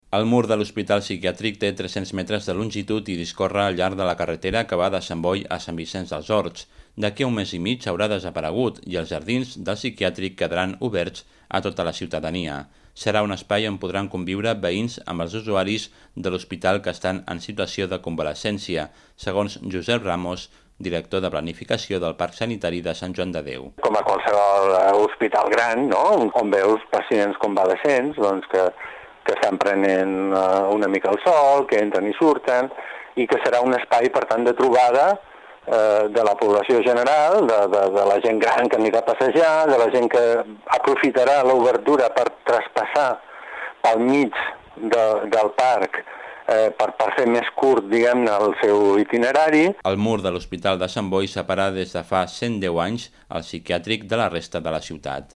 Radio Nacional de España (Informatiu Migdia - Ràdio 1 Catalunya, dissabte 18 de juny).mp3